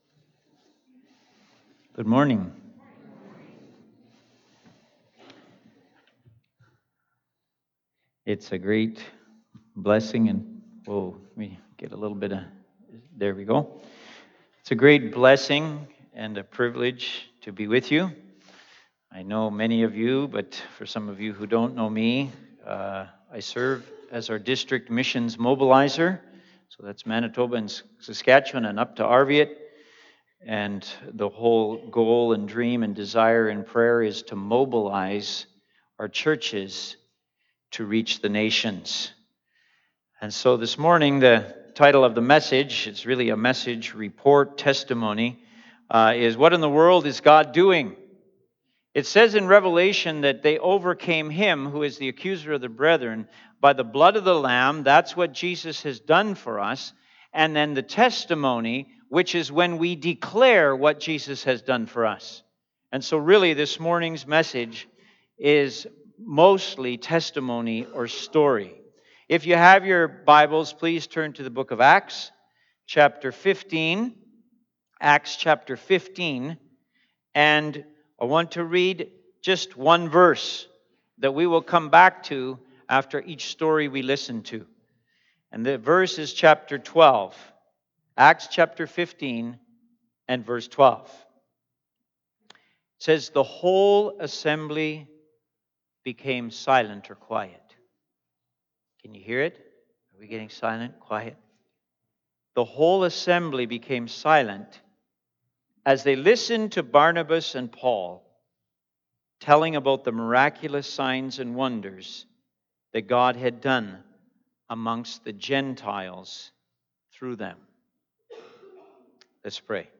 Sermons | Westgate Alliance Church